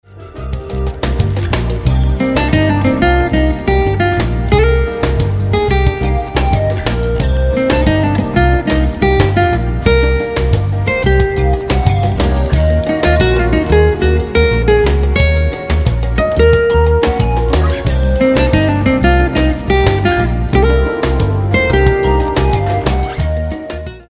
saxophones
guitars, keyboards and harmonicas
world-beat rhythms and sophisticated jazz arrangements
coastal smooth sound